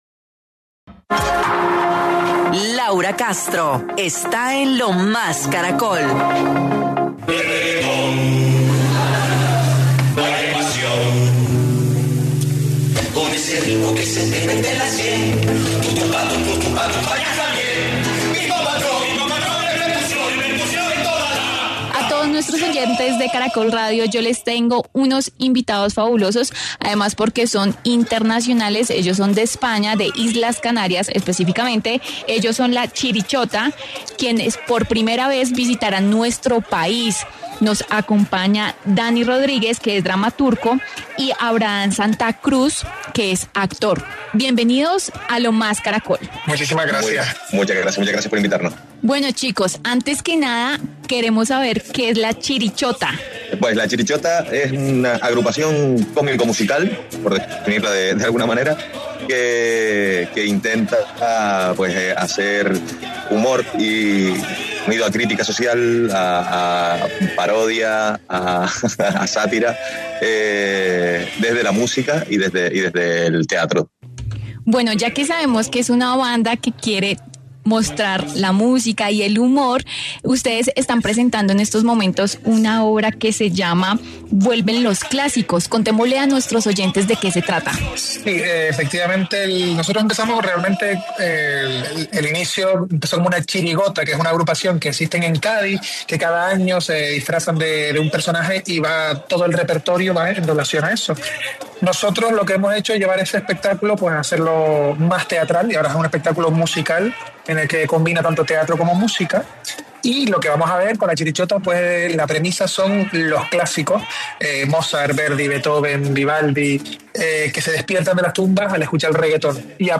En diálogo con Lo Más Caracol